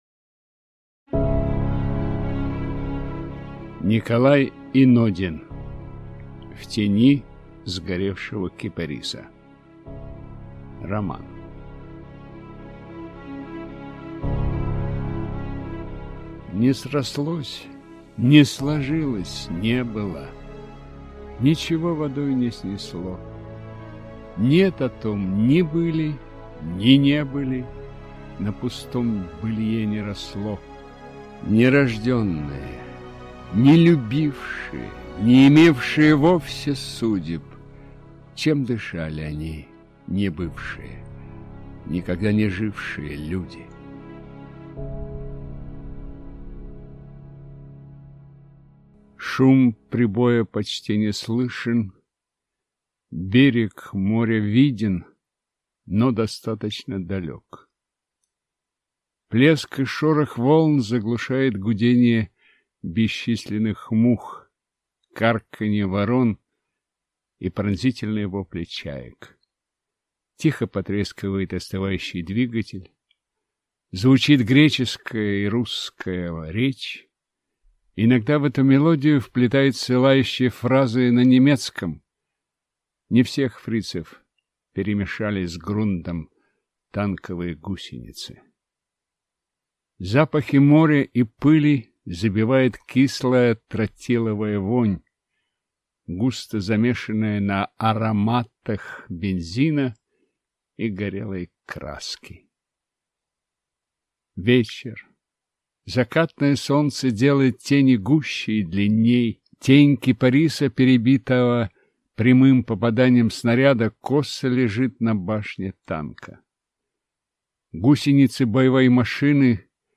Аудиокнига В тени сгоревшего кипариса | Библиотека аудиокниг